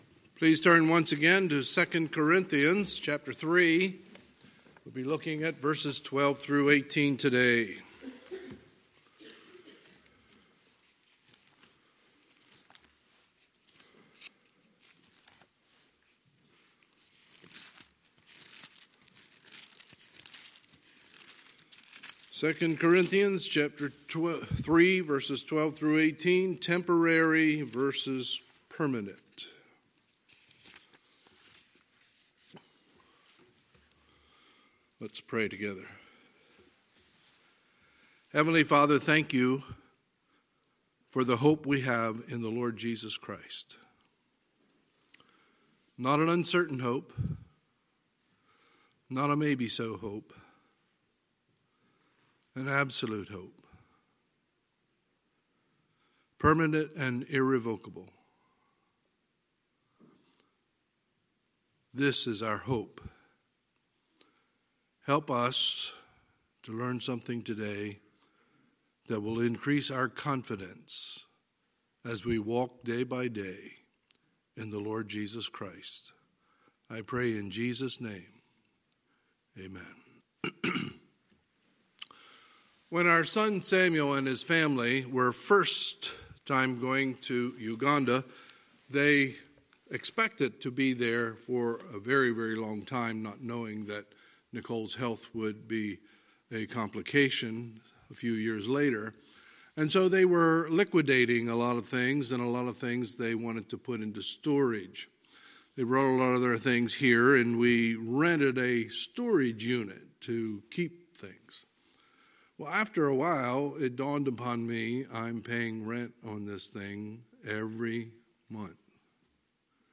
Sunday, February 23, 2020 – Sunday Morning Service